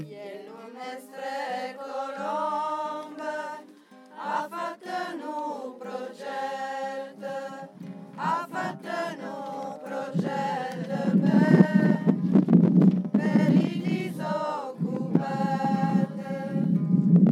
altos_couplet.mp3